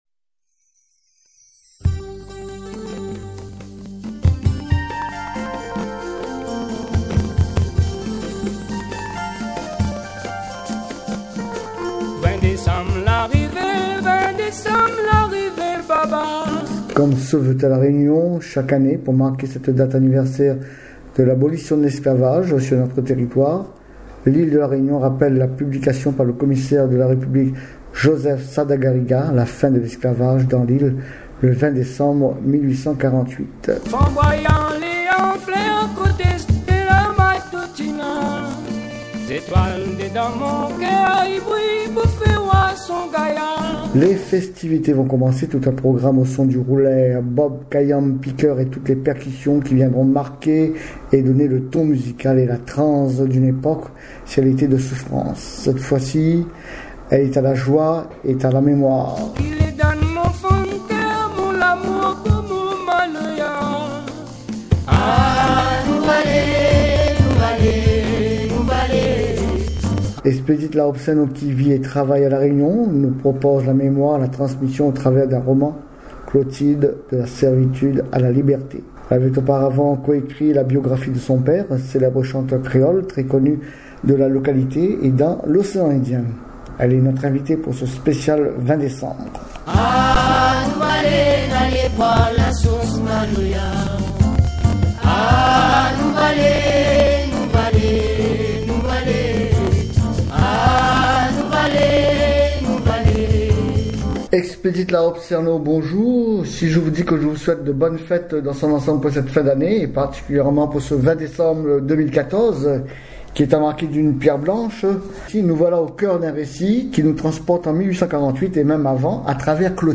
Interview radio